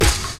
Open Hat (Gone, Gone).wav